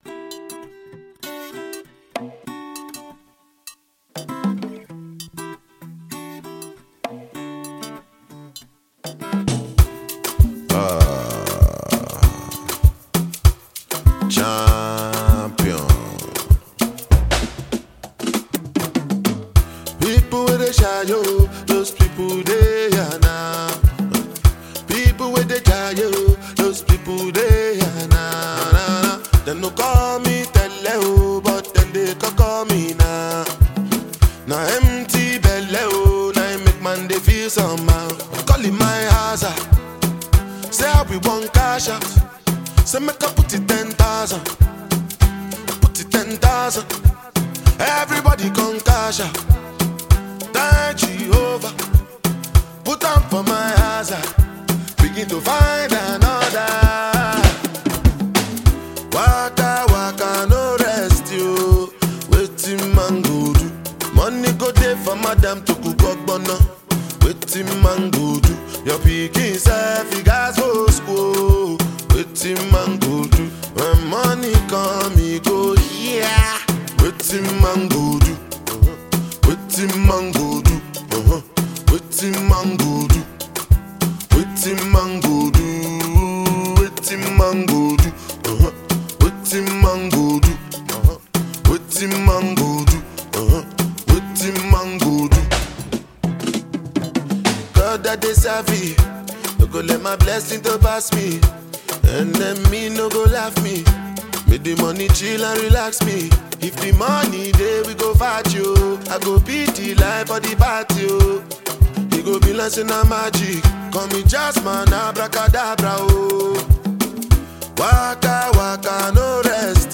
Released in 2019, it mixes Afrobeat, reggae, and hip-hop.